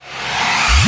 VEC3 Reverse FX
VEC3 FX Reverse 56.wav